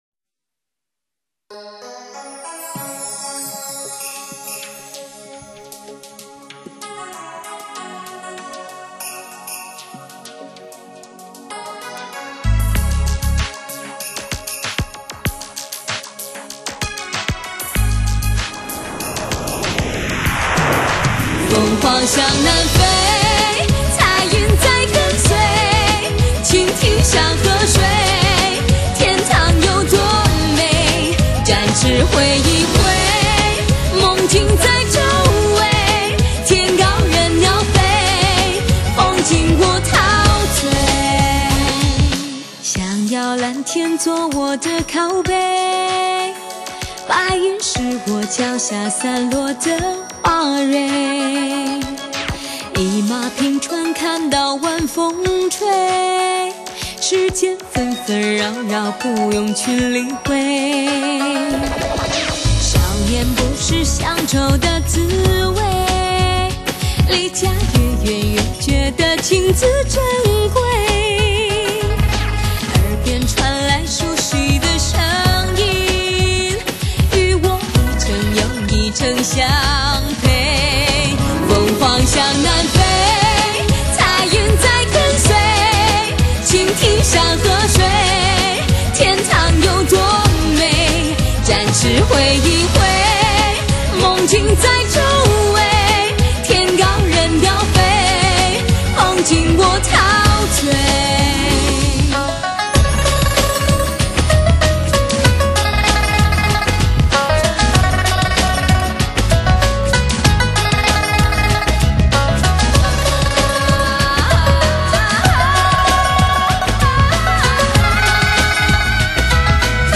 十六首女声佳音
醉美声音独具韵味 领略女人情感释放